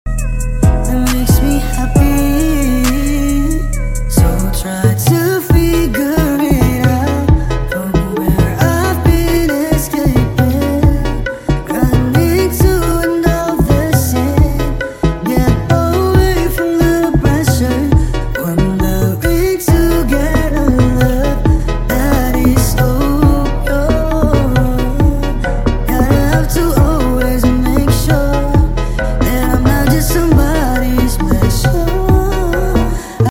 off beat